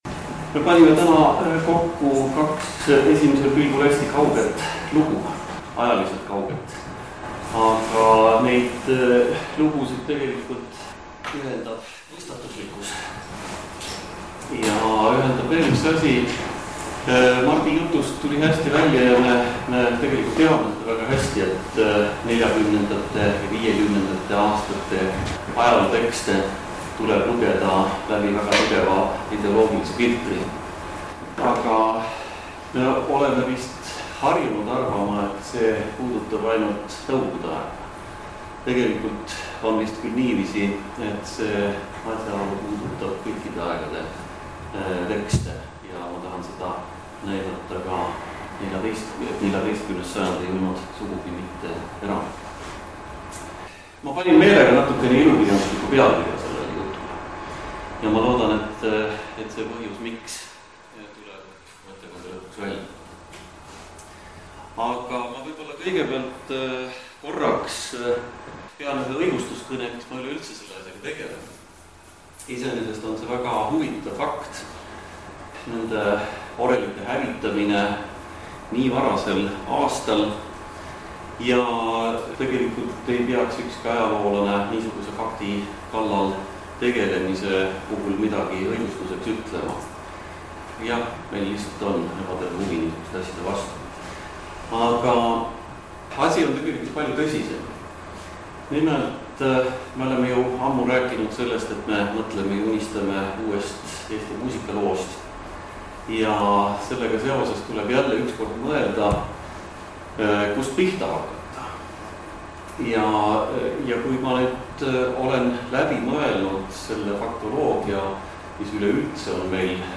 Eesti Muusikateaduse Selts » Leichteri päev 19. novembril 2012